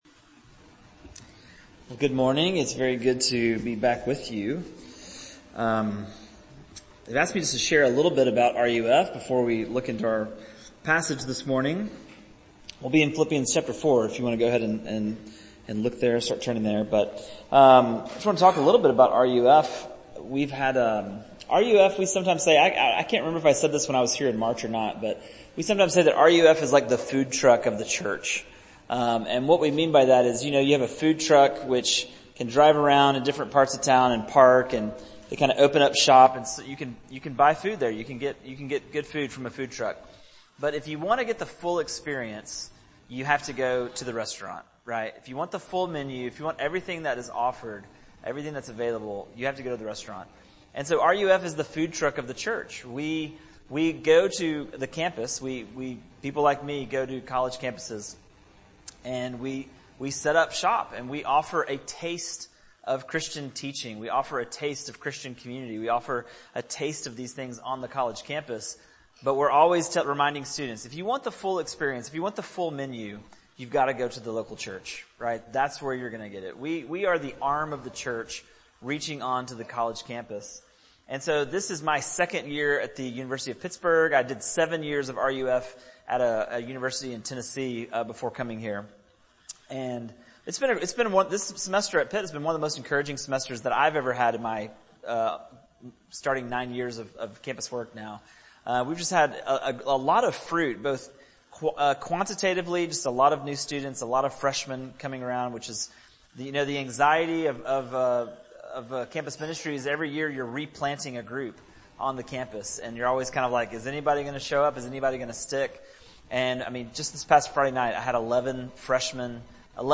Series: Guest Pastor